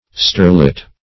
Search Result for " sterlet" : The Collaborative International Dictionary of English v.0.48: Sterlet \Ster"let\, n. [Russ. sterliade.]